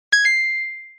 sfx_point.mp3